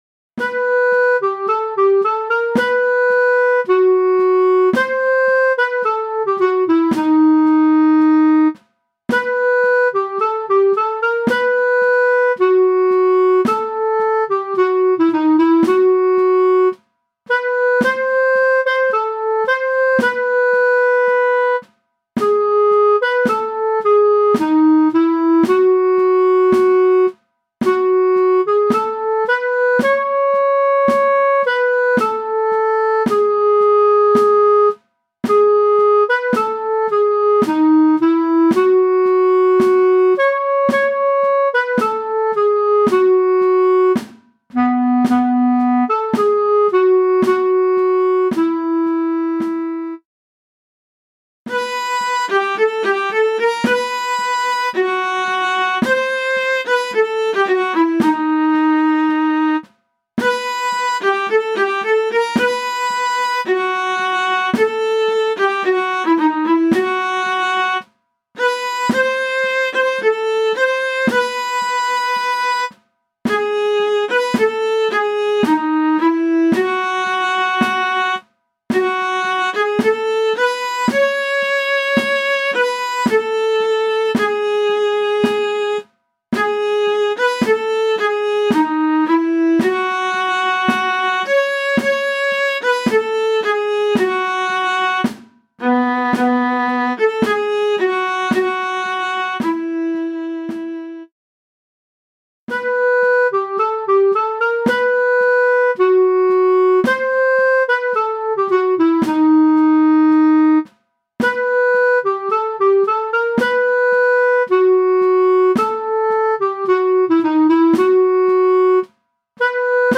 MIDI - 1-stimmig